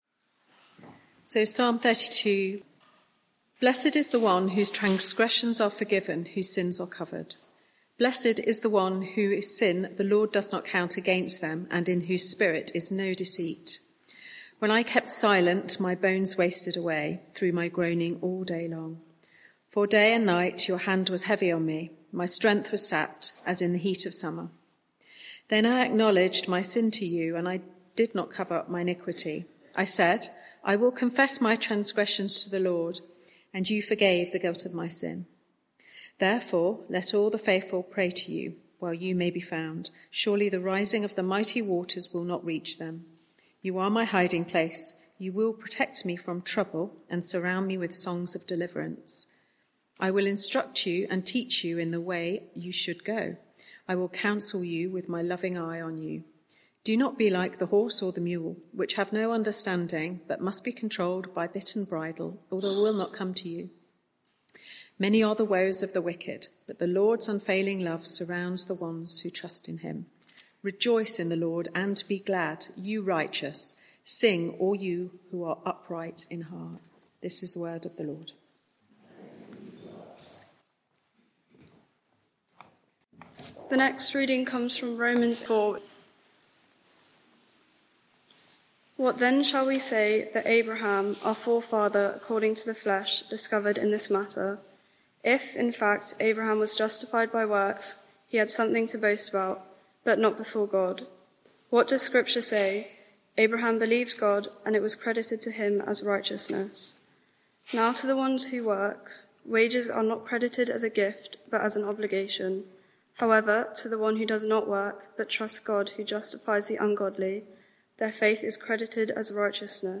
Media for 6:30pm Service on Sun 28th Jul 2024 18:30 Speaker
Passage: Psalm 32 Series: Telling God How I Feel Theme: When I’m ashamed Sermon (audio)